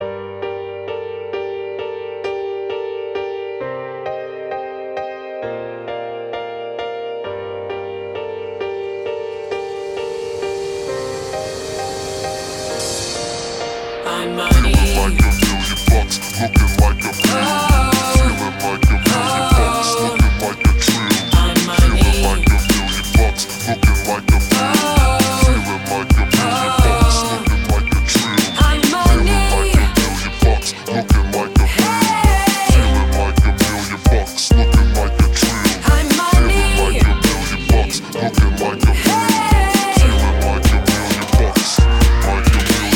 мужской вокал
красивые
Хип-хоп
пианино